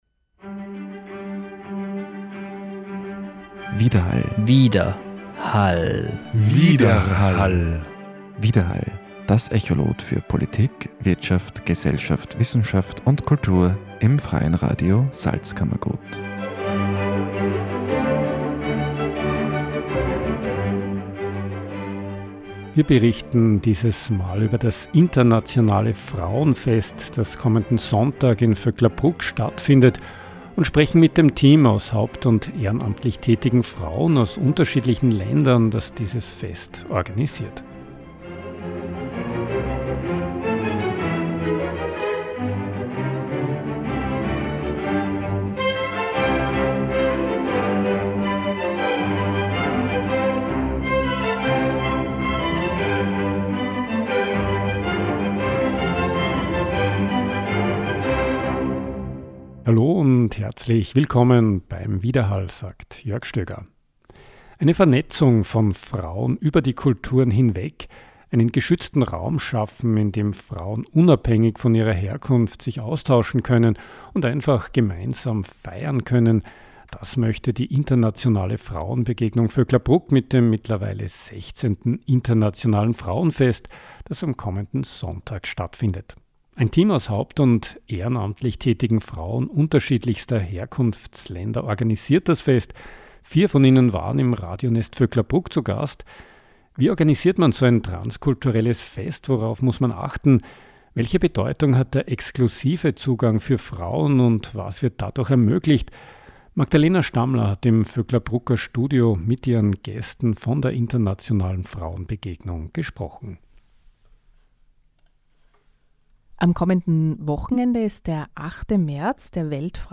Vier von ihnen waren im Radionest Vöcklabruck zu Gast und haben über ihre Aktivitäten gesprochen: Wie organisiert man so ein transkulturelles Fest,